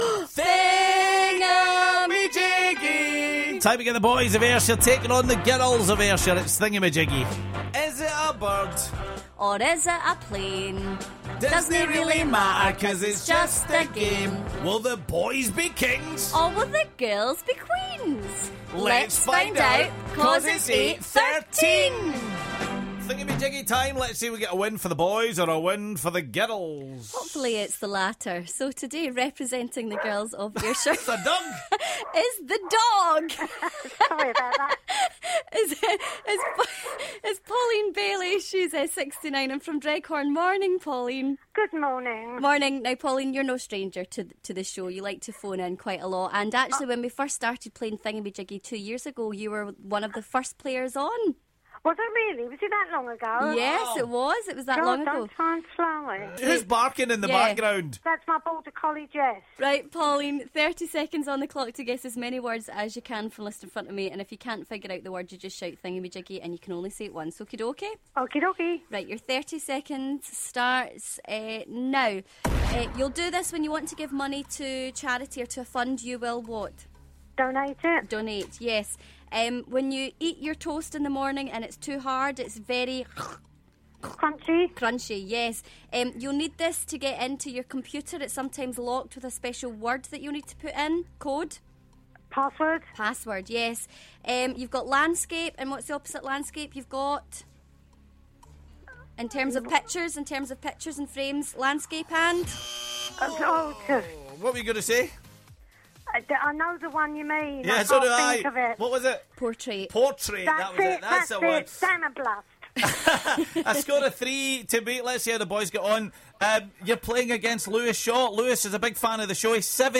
A dog took part in Thingummyjiggy for the first time this morning.